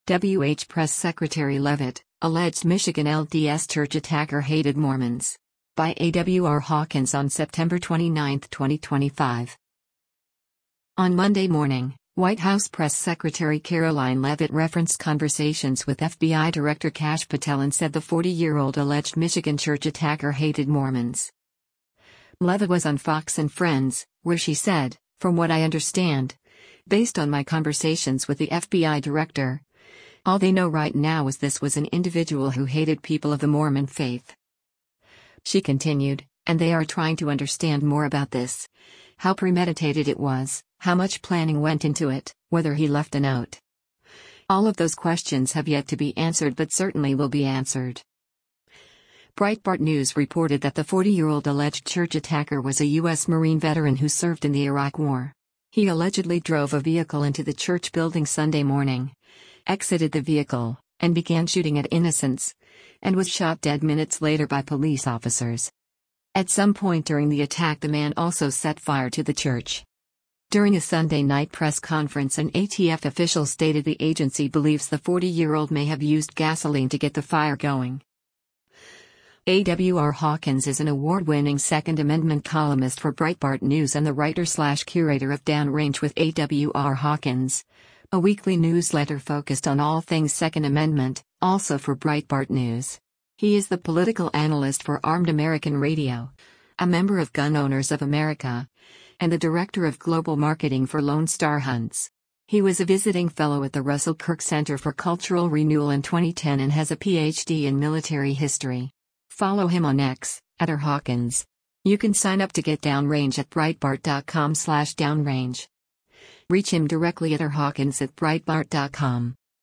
Leavitt was on FOX & Friends, where she said, “From what I understand, based on my conversations with the FBI director, all they know right now is this was an individual who hated people of the Mormon faith.”